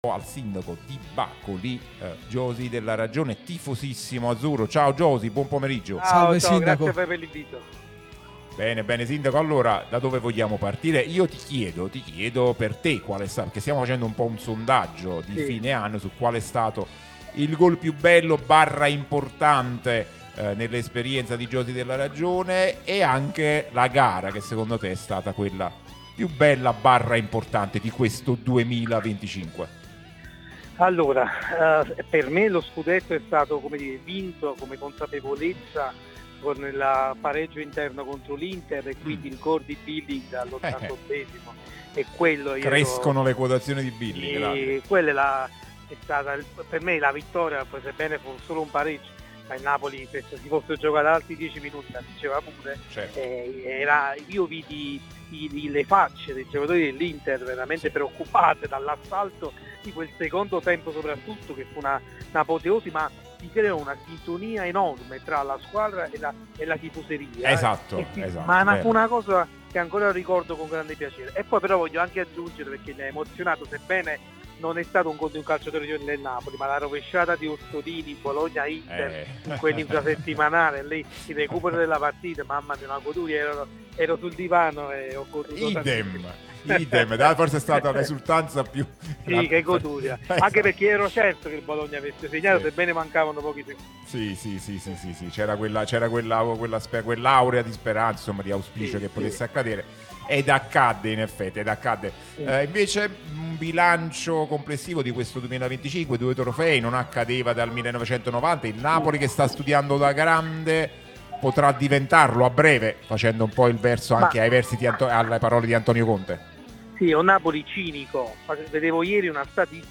Josi Gerardo Della Ragione, sindaco di Bacoli e grandissimo tifoso del Napoli, è intervenuto nel corso di 'Cronache azzurre' sulla nostra Radio Tutto Napoli, prima radio tematica sul Napoli, in onda tutto il giorno, che puoi ascoltare/vedere qui sul sito o sulle app (qui per Iphone/Ipad o qui per Android).